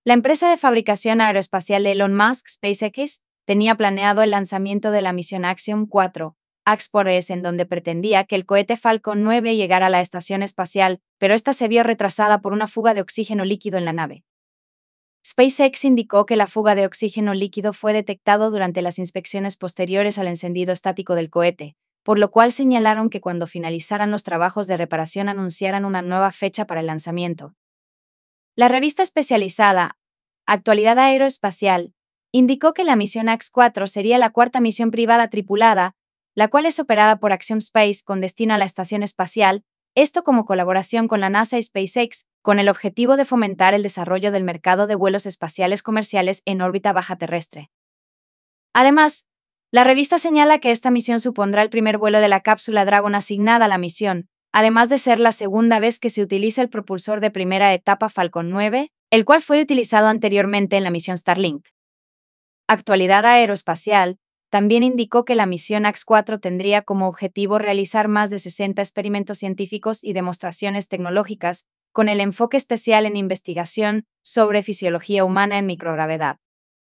PlayAI_La_empresa_de_fabricacio_n_aeroespacial_de.wav